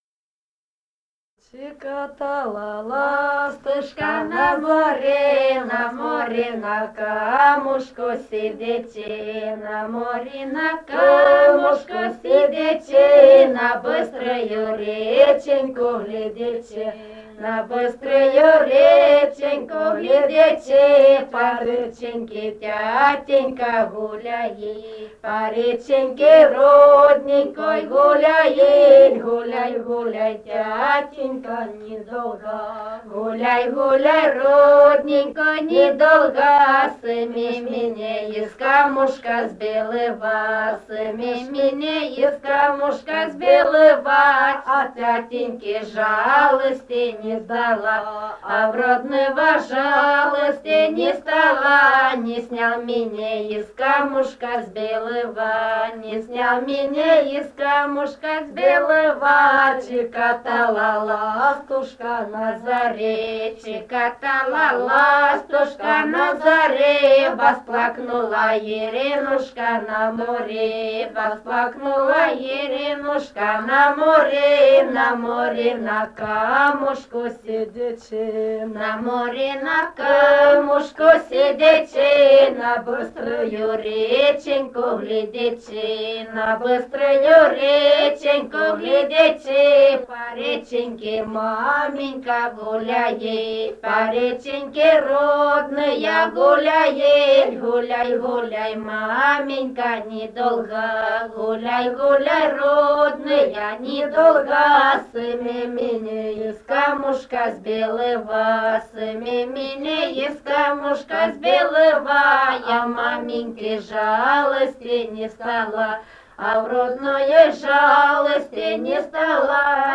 полевые материалы
Румыния, с. Переправа, 1967 г. И0974-02